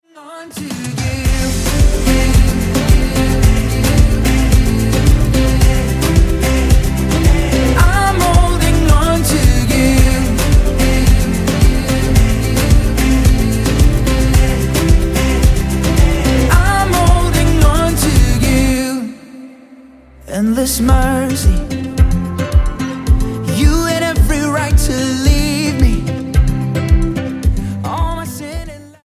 STYLE: Pop
bass and guitar riff
hugely catchy pop clearly targeted at the young